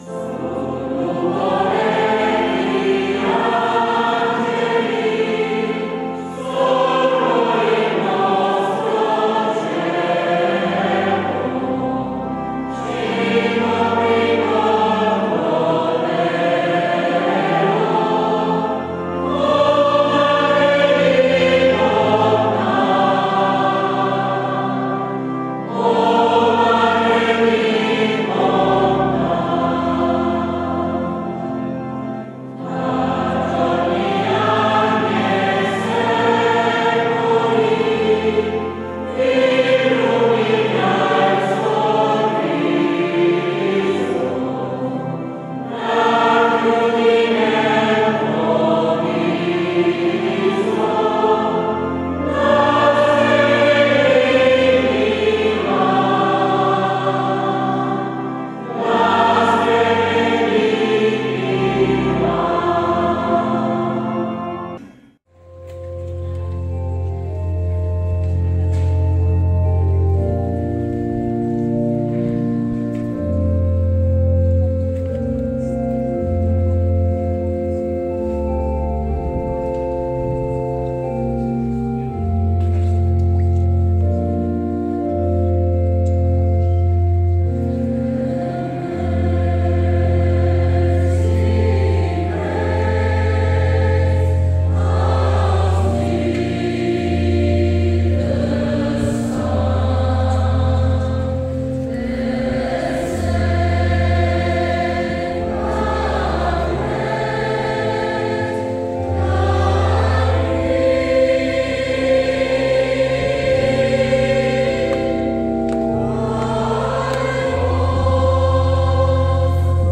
Il repertorio è molto vasto e comprende musica sacro-religiosa, operistica, madrigalistica, folkloristica, gospel, spiritual e classici della musica leggera.
L’organico attuale è composto da oltre 120 elementi costituenti il Primo Coro e il Coro delle Voci Bianche.
CORALE.mp3